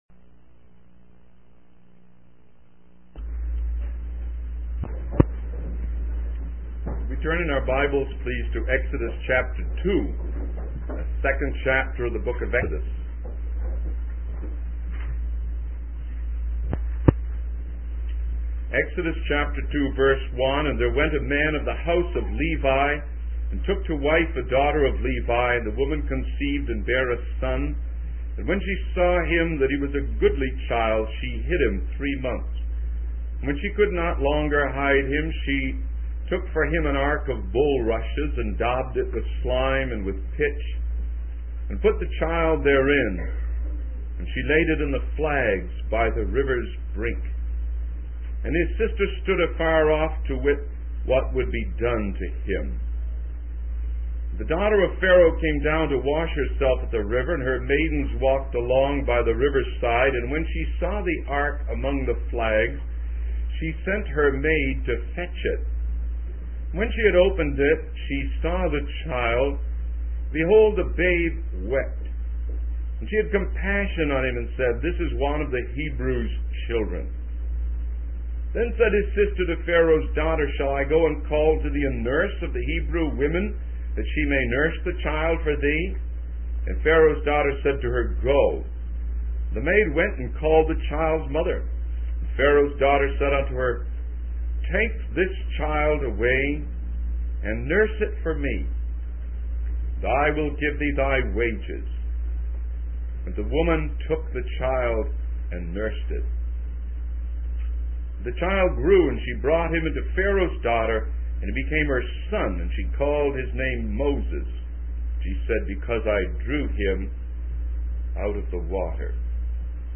In this sermon, the speaker addresses the issue of parents prioritizing their children's careers over their commitment to the work of the Lord. He emphasizes the importance of the Great Commission and the call to preach the gospel to every creature.